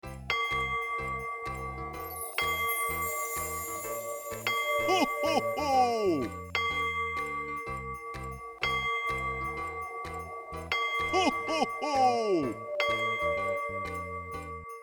cuckoo-clock-07.wav